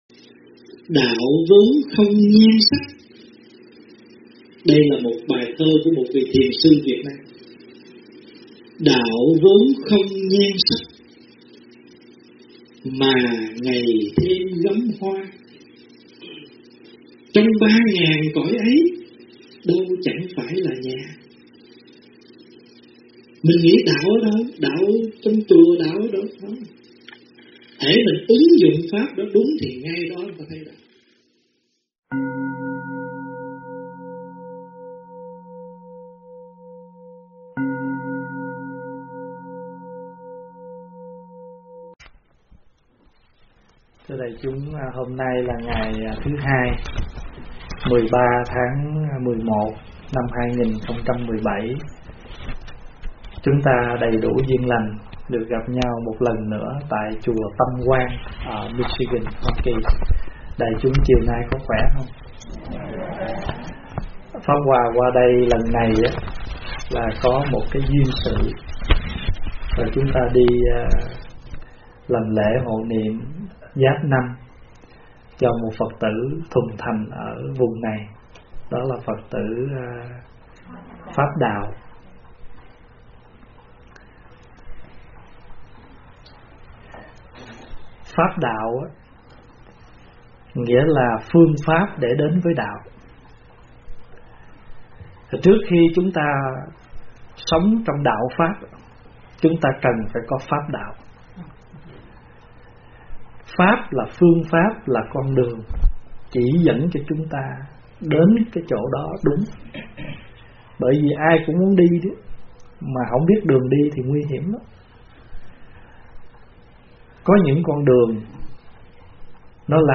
Mp3 Thuyết Pháp Pháp Đạo – Đạo Pháp Là Gì? – Đại Đức Thích Pháp Hòa thuyết giảng tại Chùa Tâm Quang, Bang Michigan, Hoa Kỳ, ngày 13 tháng 11 năm 2017